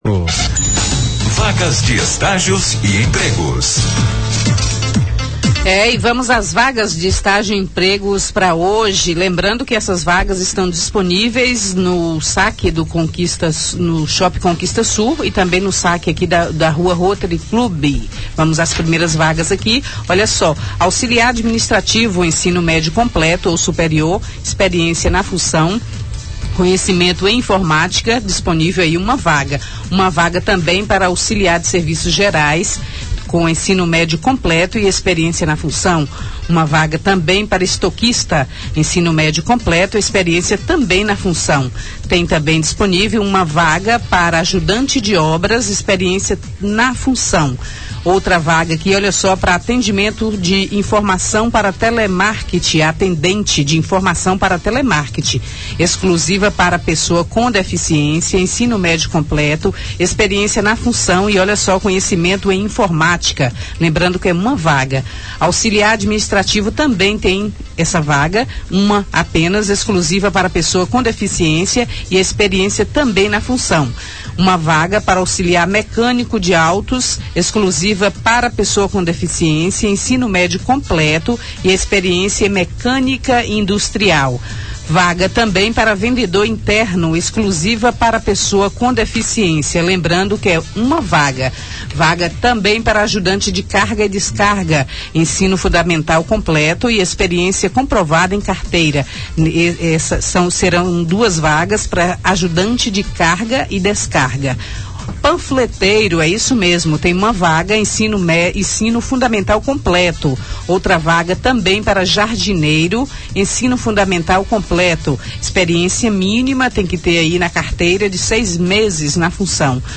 Na sonora, a jovem traz todos os destaques de empregos e estágios em Vitória da Conquista.